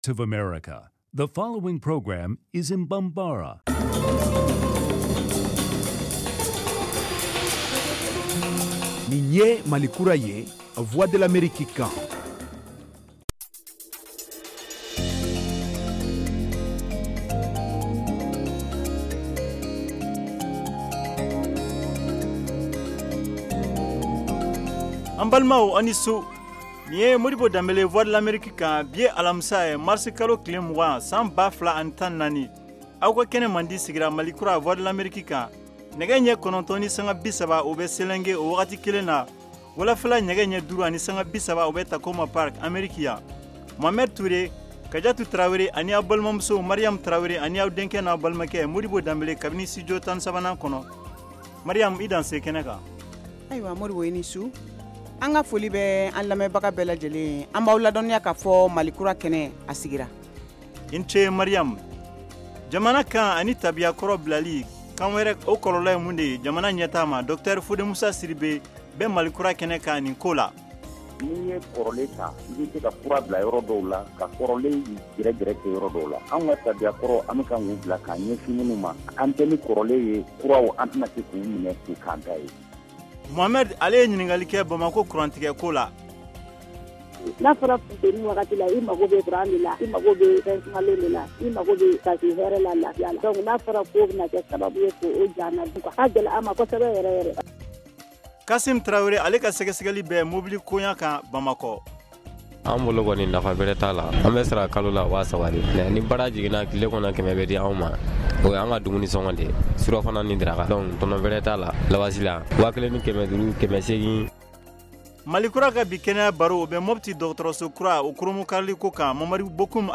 Emission quotidienne
en direct de Washington. Au menu : les nouvelles du Mali, les analyses, le sport et de l’humour.